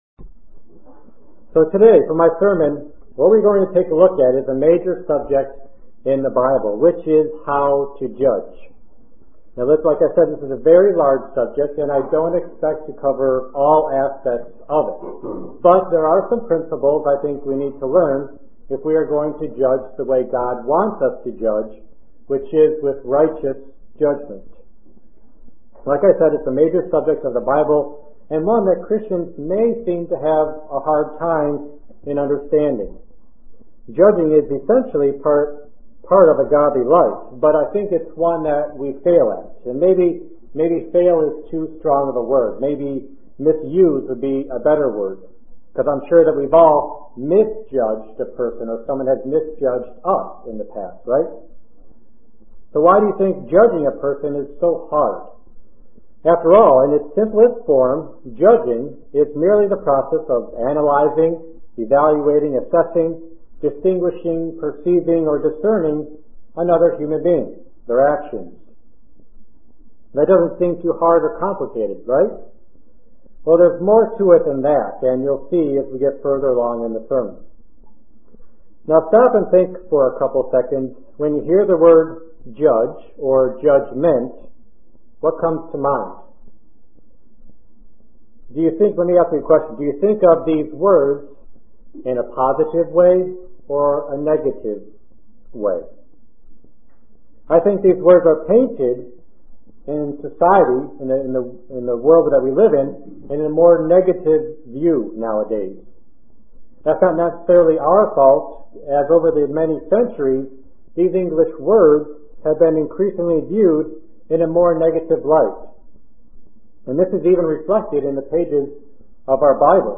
Print Judge with righteous judgment UCG Sermon Studying the bible?